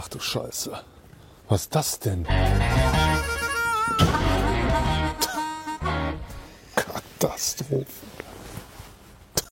Tomos A35 with cylinder stucked!